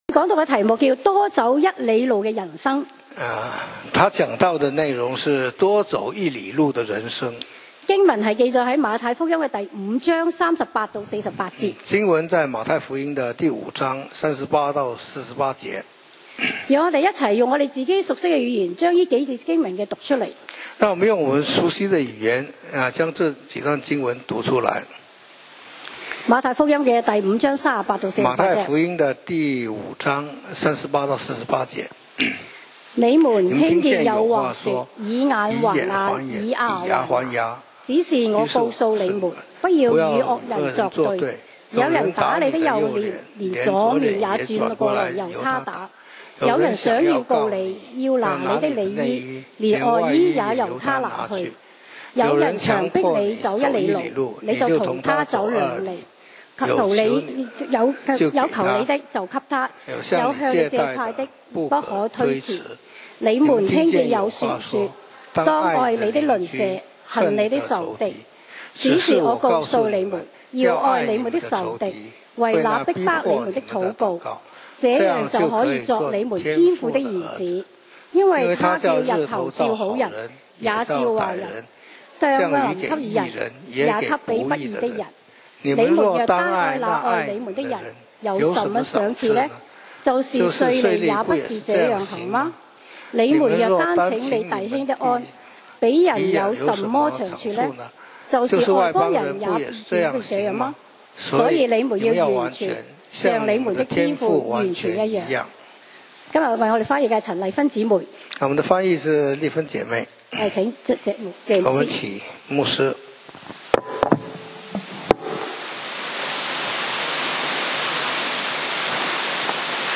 講道 Sermon 題目 Topic：多走一哩路的人生 經文 Verses：馬太福音 5: 38-48. 38你们听见有话说，以眼还眼，以牙还牙。